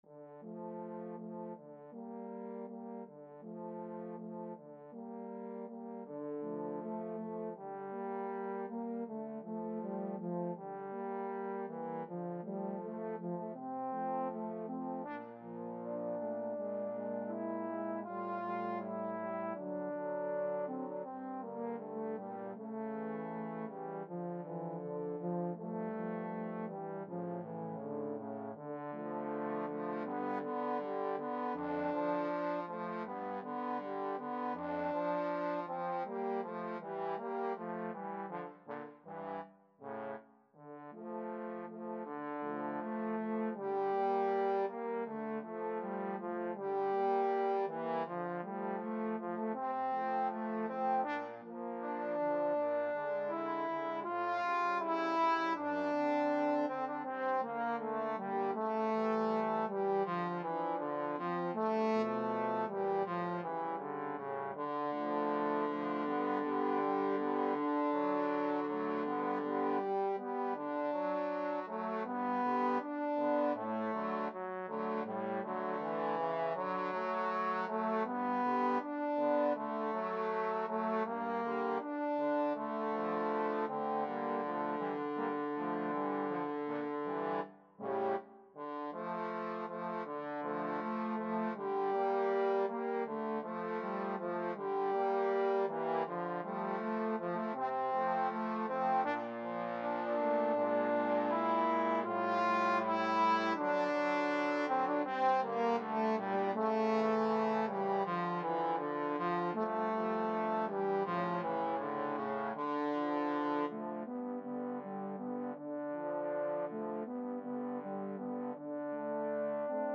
2/4 (View more 2/4 Music)
~ = 100 Andante
Classical (View more Classical Trombone Trio Music)